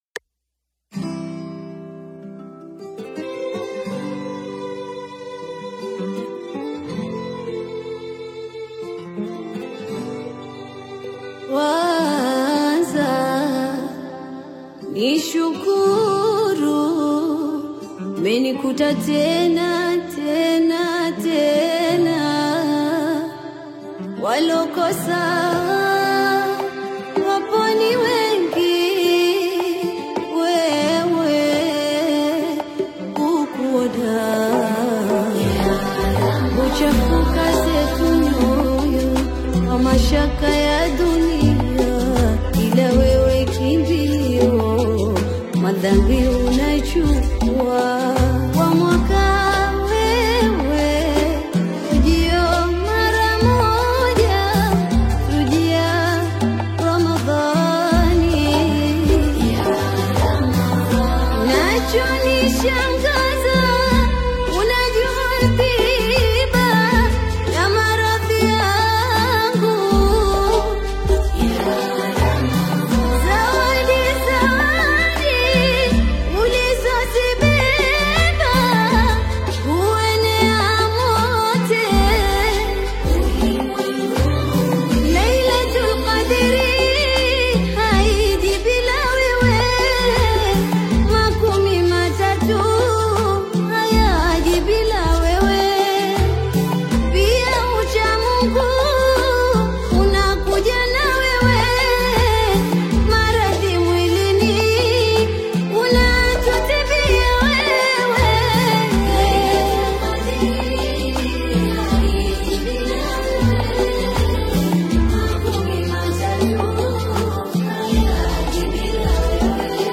QASWIDA